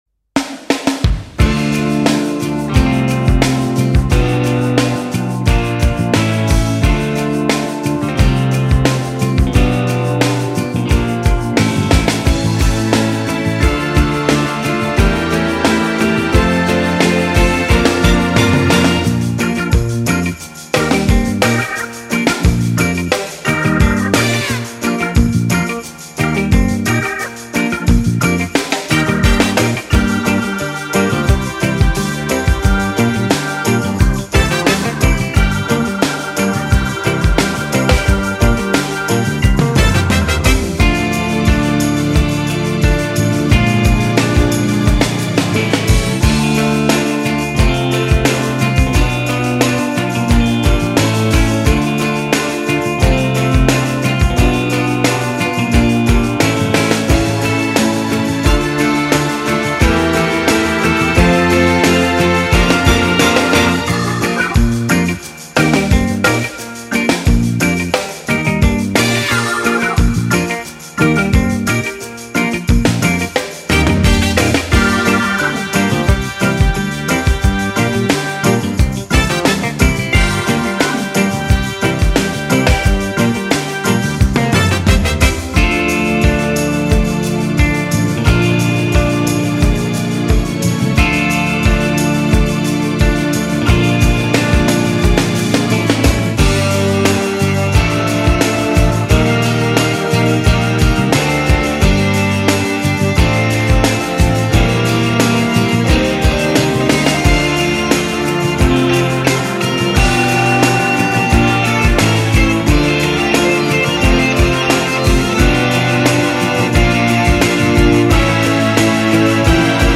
dynamique
reggae - guitare electrique - pop